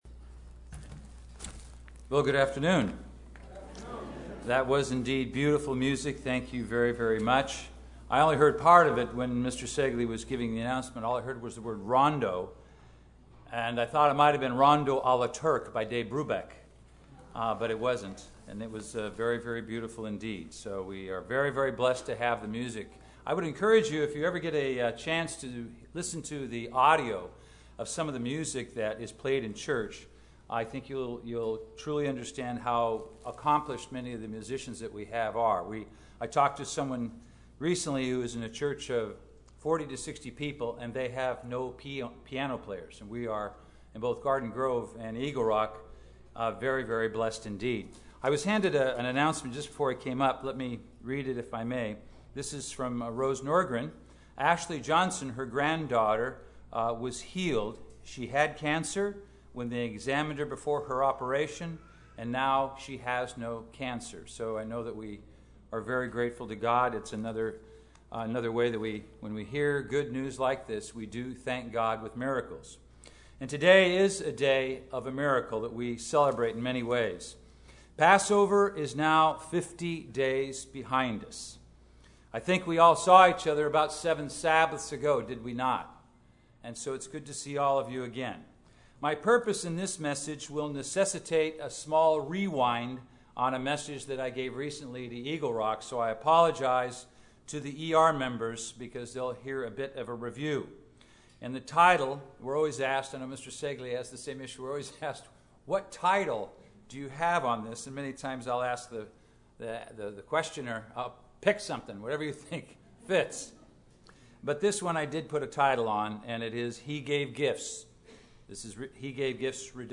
The second of two sermons on spiritual gifts. This message discusses how to edify the Church by utilizing and developing the gifts God gives.
Given in Los Angeles, CA